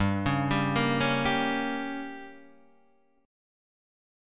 G Diminished